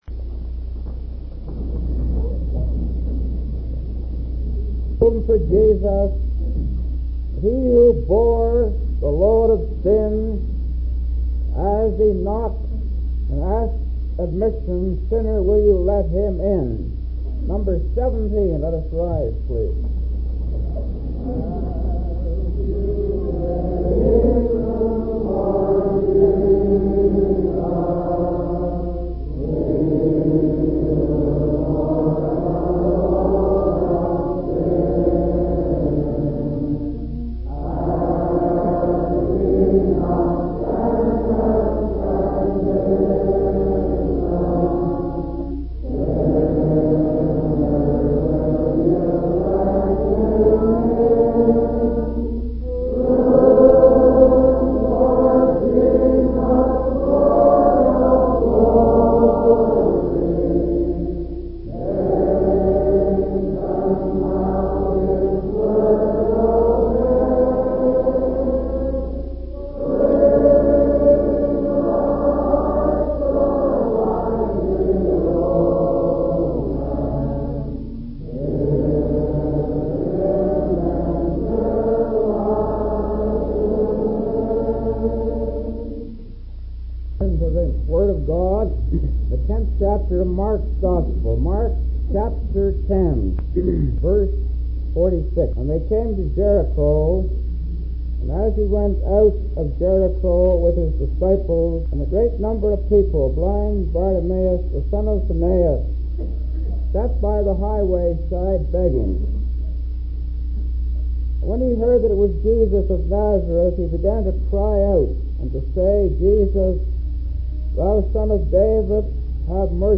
1968 Revival Meeting